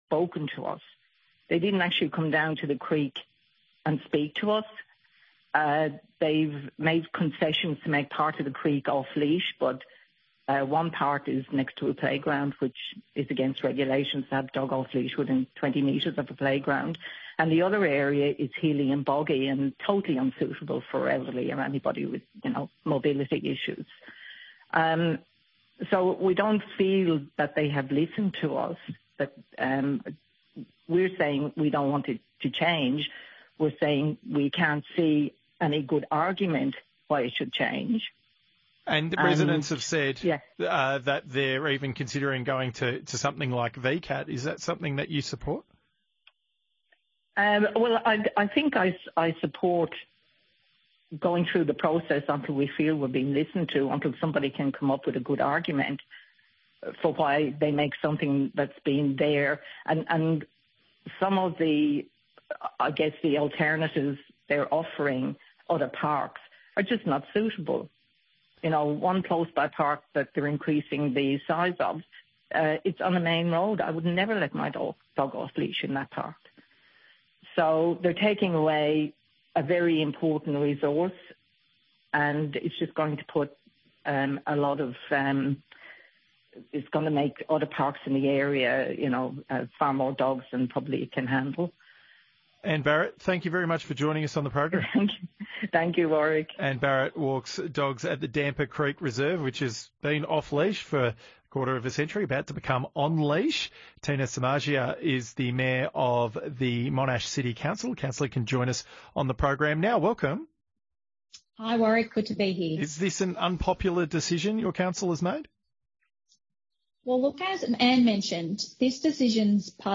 Media Interview - ABC Drive dogs off-leash